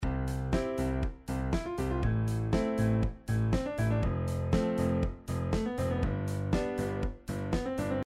Четыре доли, по длительности - четвертные.
Для красоты я решил добавить даже шестнадцатых нот.
Pop.mp3